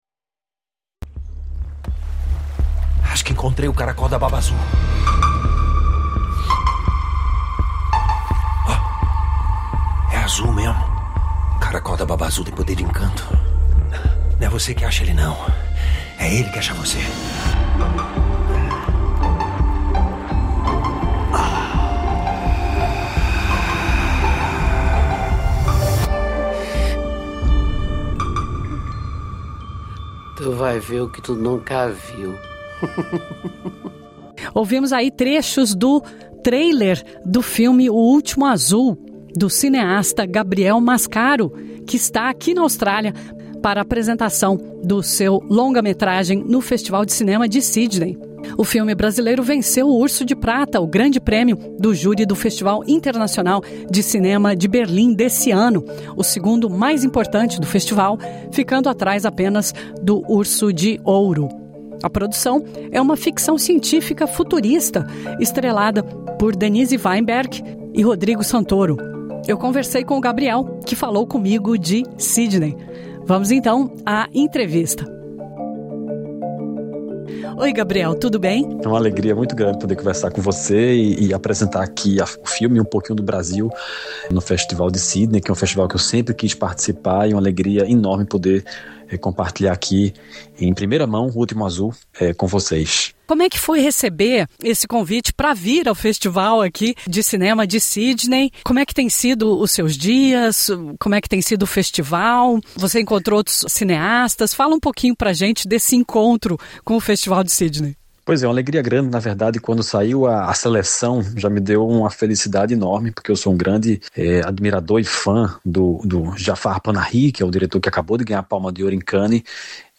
O cineasta brasileiro Gabriel Mascaro está na Austrália para a apresentação do seu longa-metragem 'O Último Azul' no Festival de Cinema de Sydney e falou à SBS em Português.